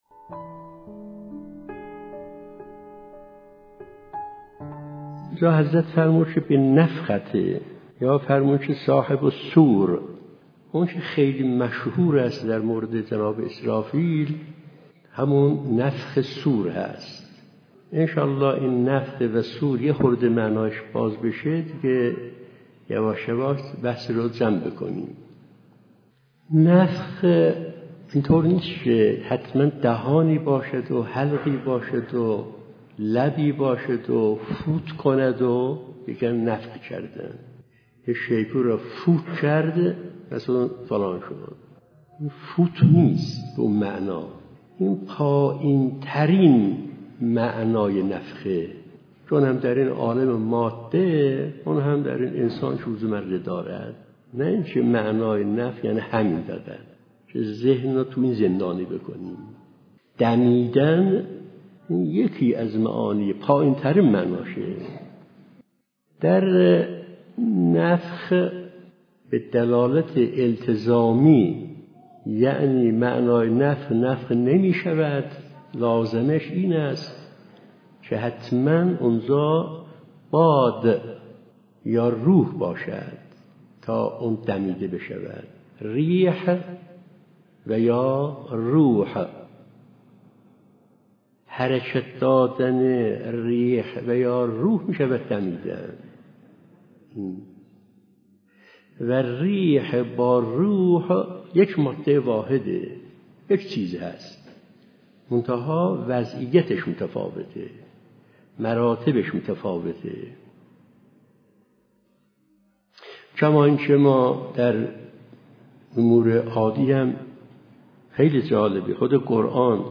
سلسله مباحث ملائکه‌شناسی